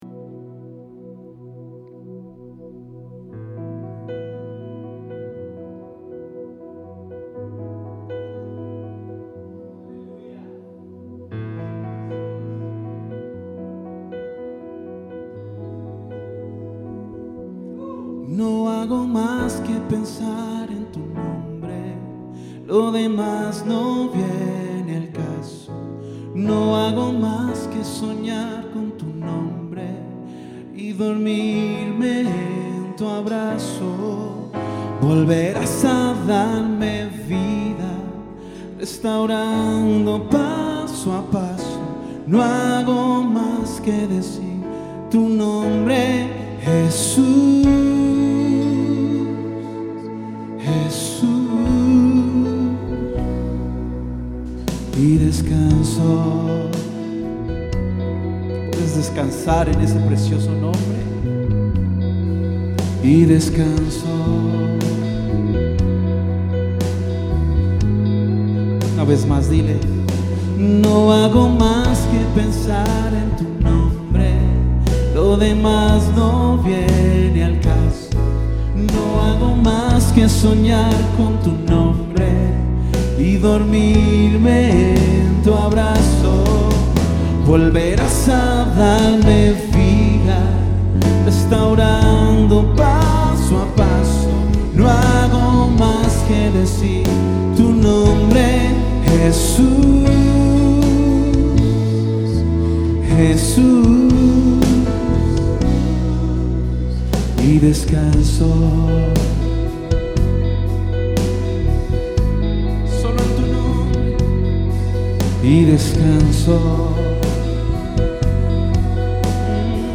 Tu Nombre (Ministracion